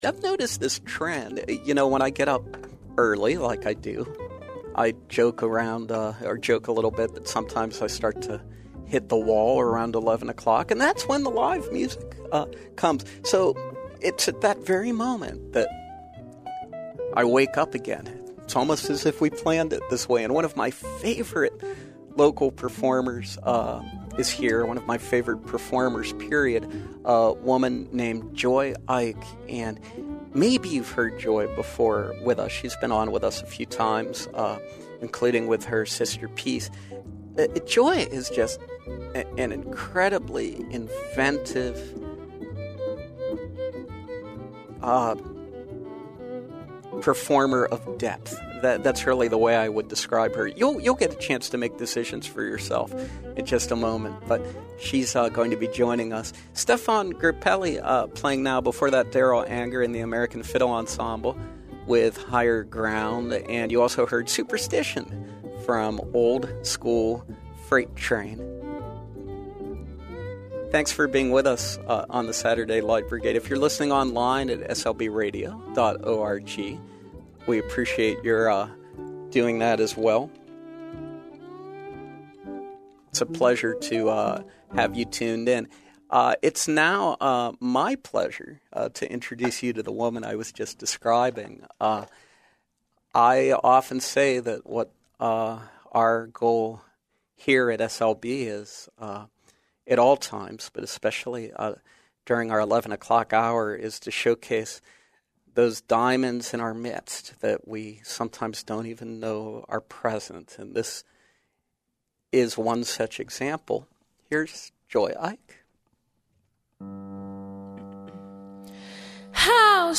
back to our studios. A skillful singer and pianist
soul, pop and jazz